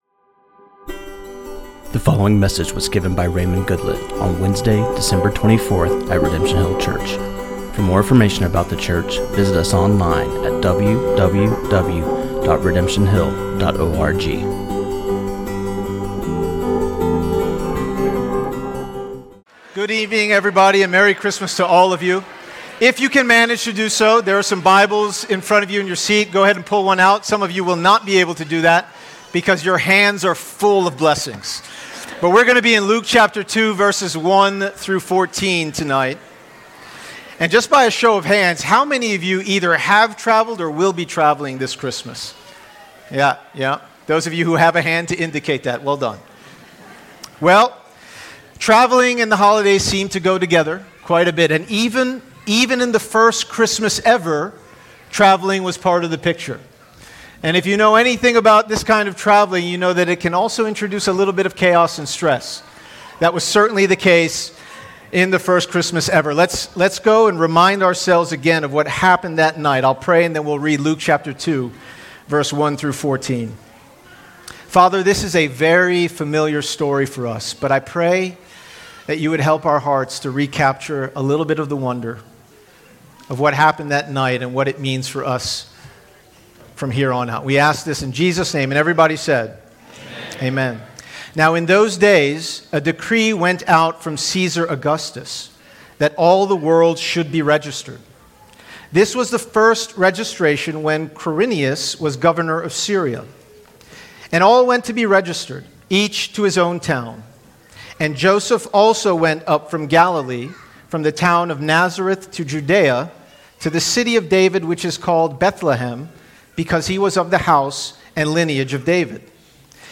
This sermon on Luke 2:1-14